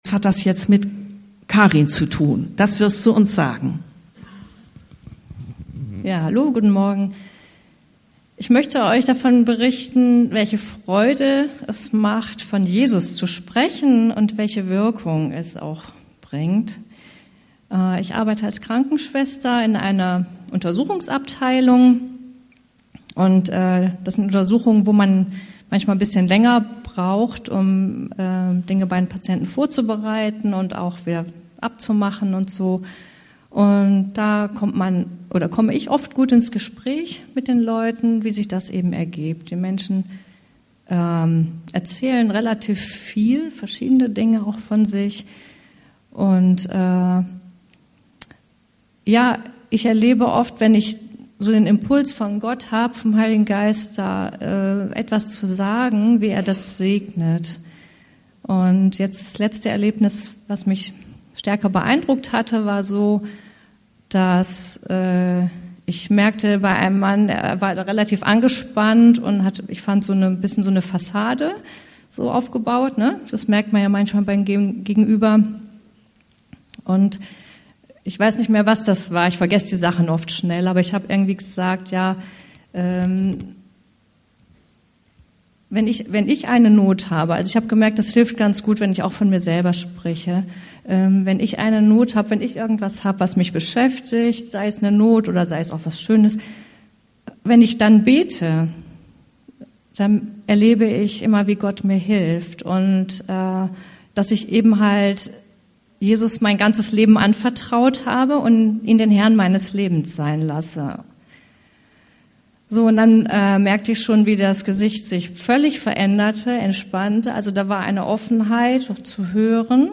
Predigtreihe: „Evangelium“: Das Leben Gottes finden- entfalten-weitergeben